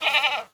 goat_call_05.wav